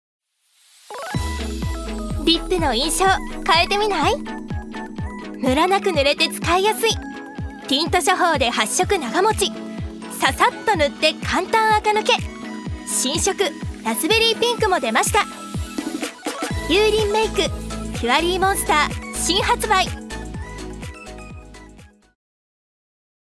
女性タレント
音声サンプル
ナレーション１